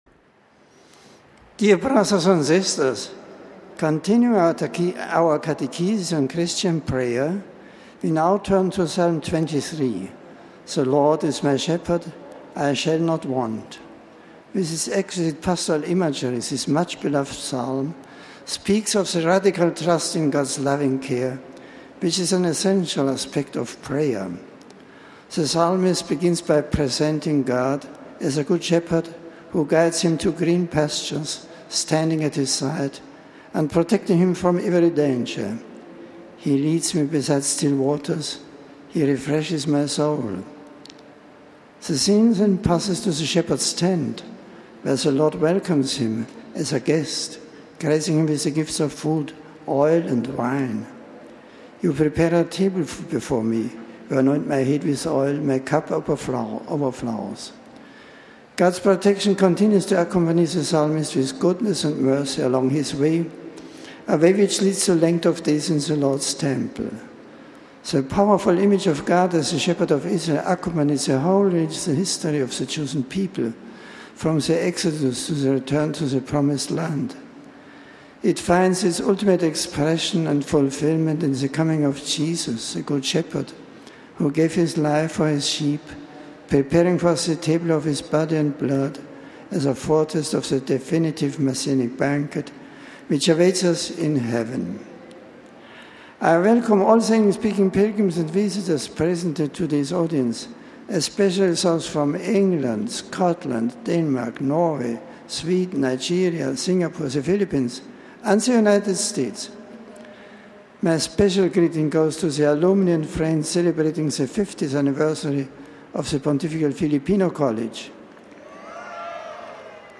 The general audience of Oct. 5 was held in the open in St. Peter’s Square. It began on the reading of a part of Psalm 23 in several languages. An aide addressed the Pope on behalf of the English speaking pilgrims introducing the various groups to him. Pope Benedict then delivered a discourse in English: